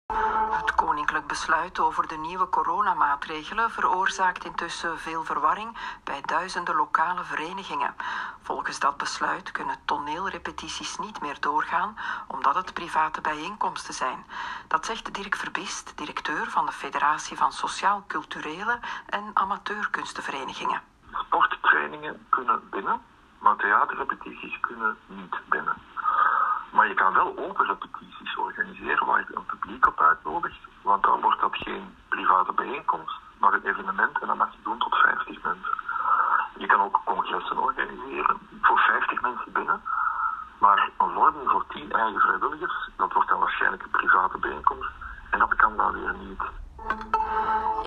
kort woordje uitleg geven op VRT radio en op zondag schonk het VRT-journaal er aandacht aan.